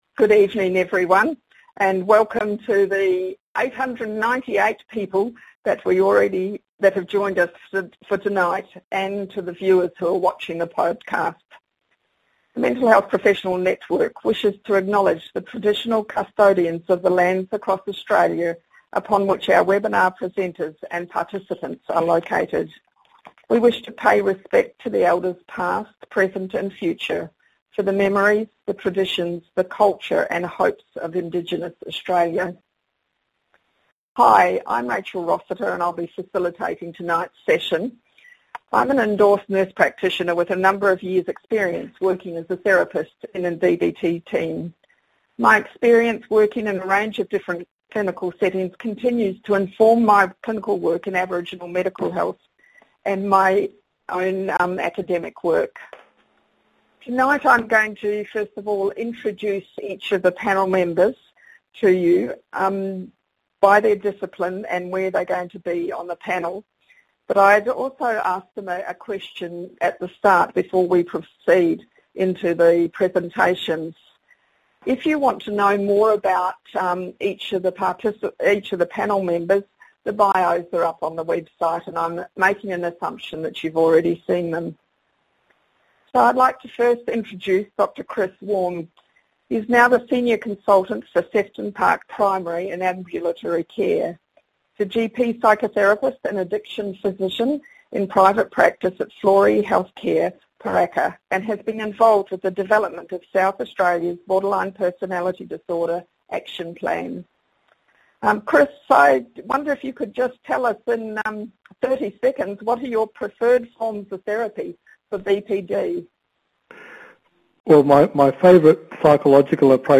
Join our interdisciplinary panel of experts for a discussion on how collaborative care may help support people who are affected by borderline personality disorder.